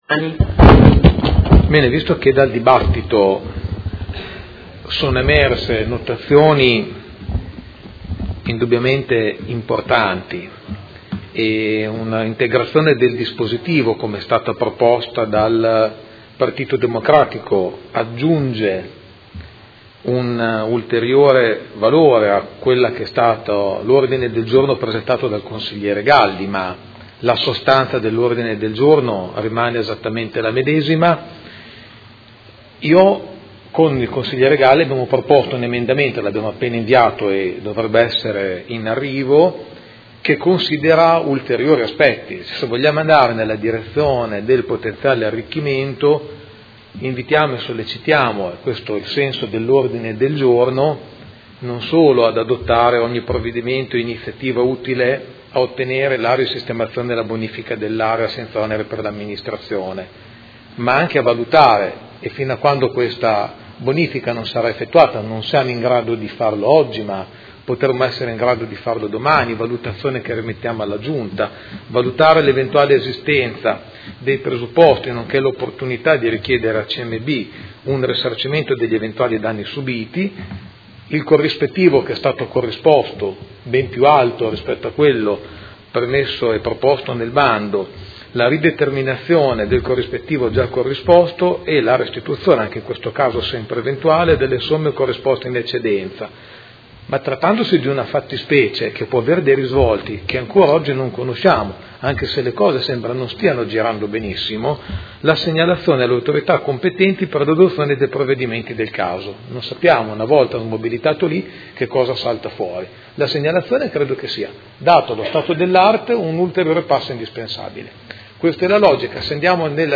Seduta del 26/03/2018 Dibattito. Ordini del giorno Rotatoria di via Emilia Est